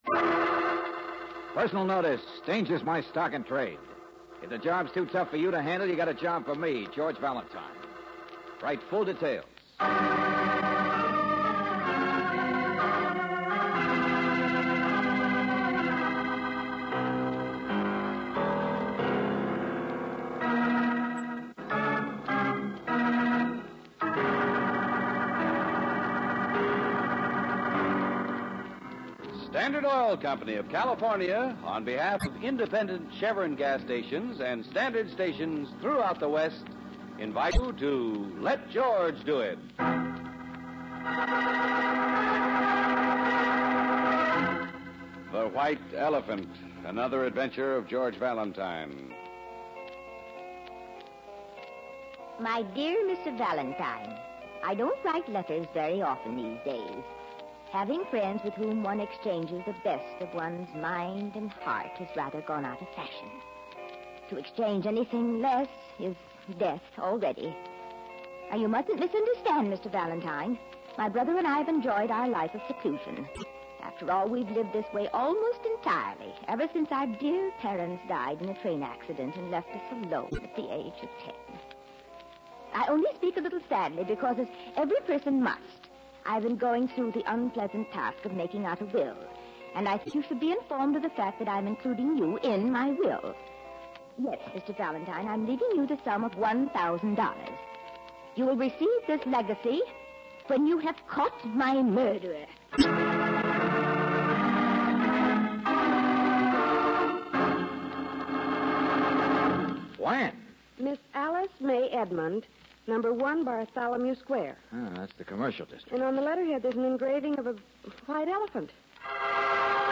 Let George Do It Radio Program